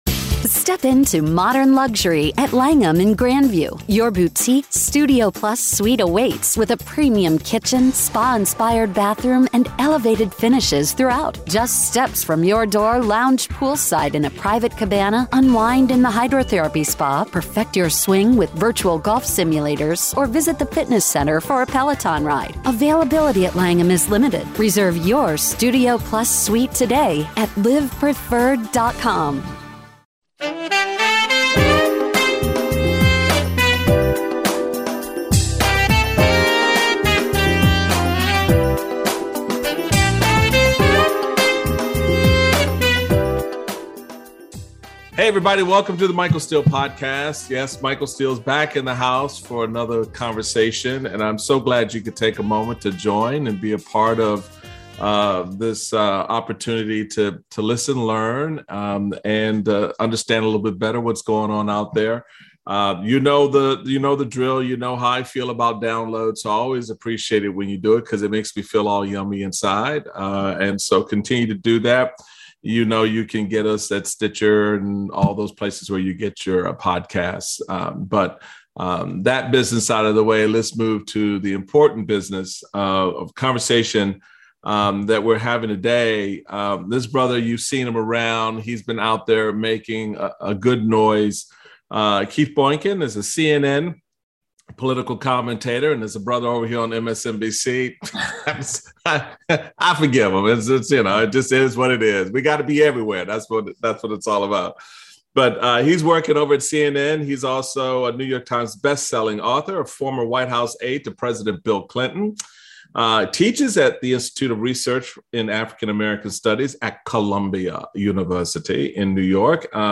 Michael speaks with Keith Boykin about the race to stop the darkening of America engaged in by some conservative white segments of the population.